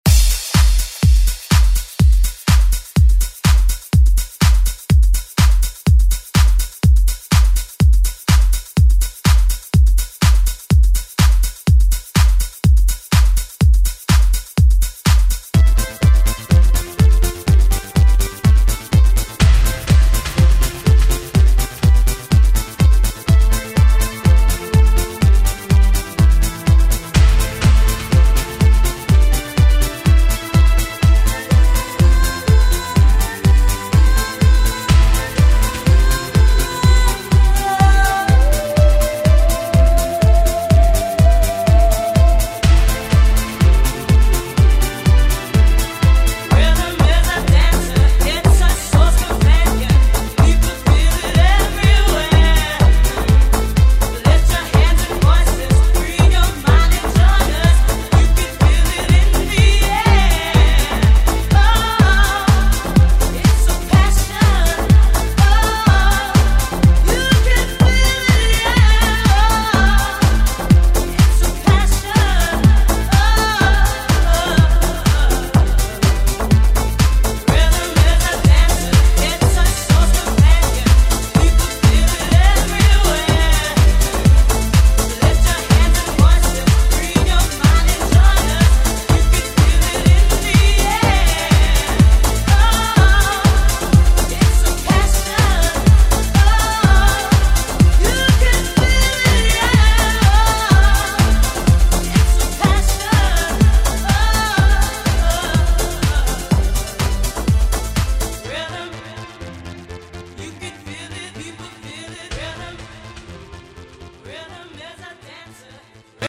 Throwback Pop Rock Music Extended ReDrum Clean 123 bpm